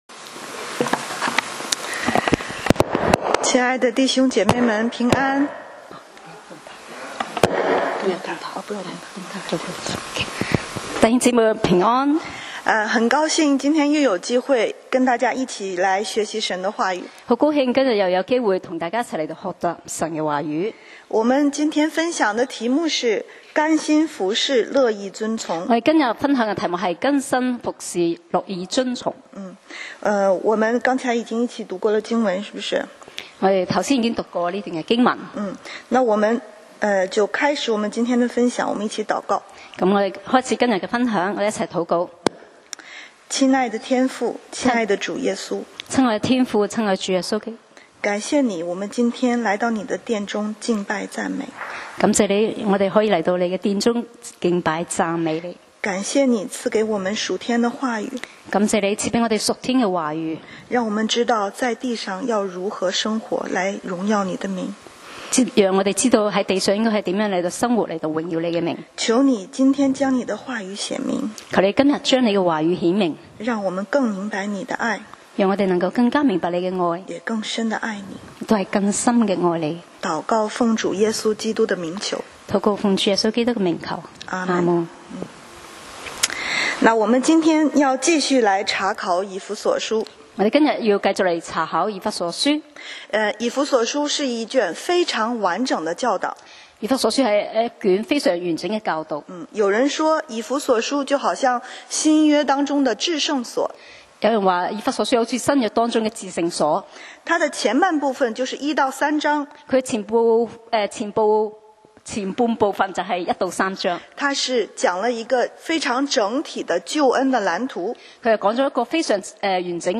講道 Sermon 題目 Topic：甘心服侍，乐意尊行.